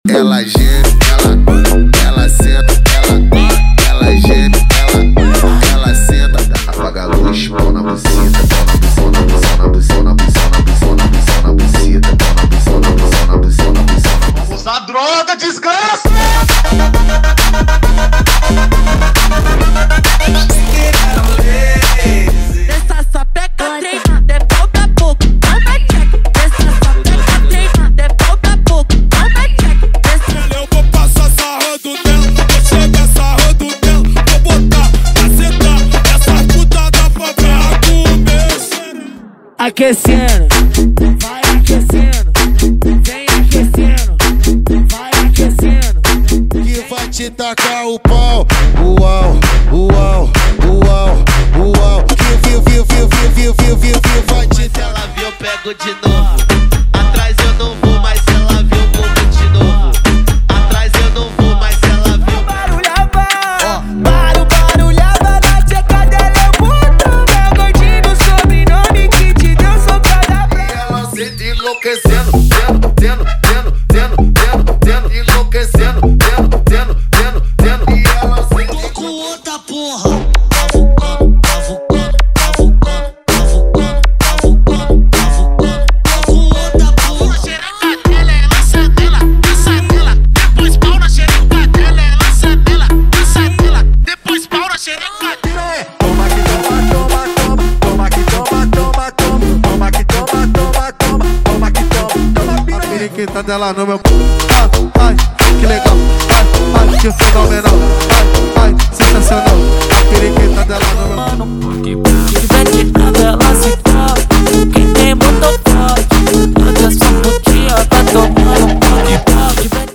Versão Proibidão
✔ Músicas sem vinhetas